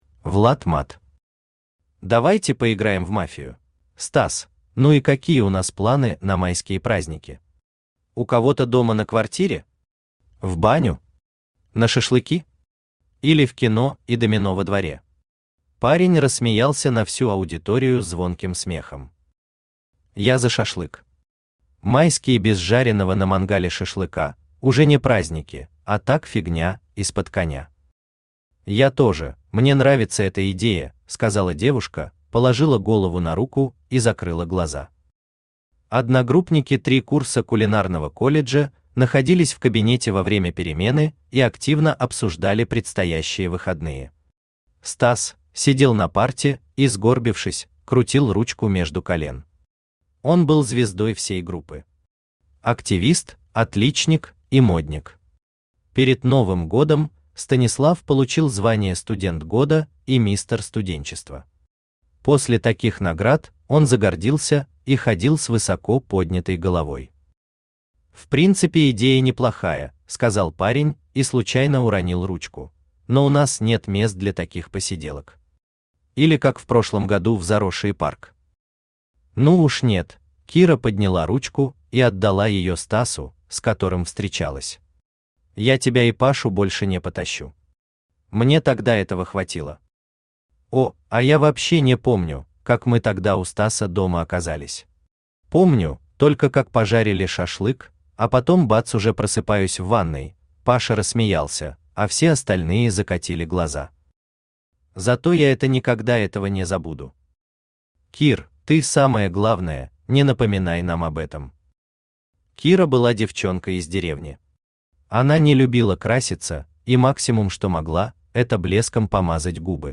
Аудиокнига Давайте поиграем в «Мафию»?
Автор Влад Матт Читает аудиокнигу Авточтец ЛитРес.